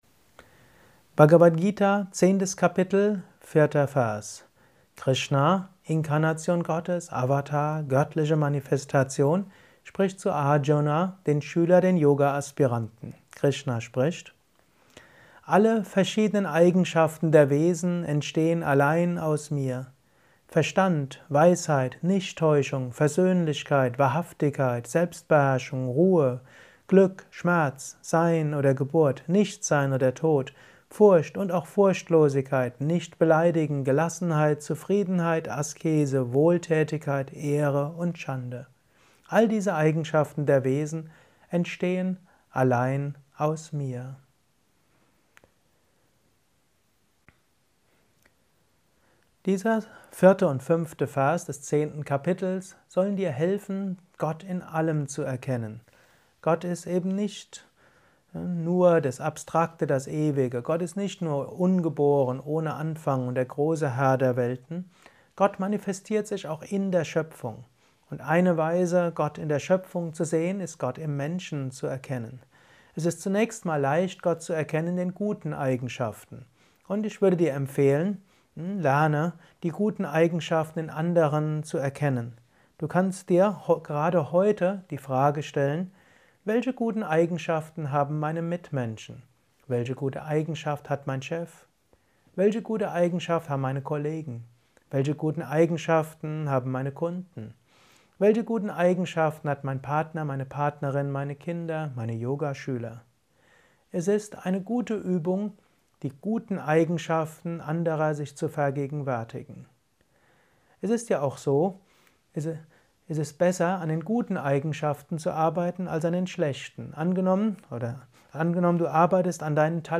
Kurzvortrag über die Bhagavad Gita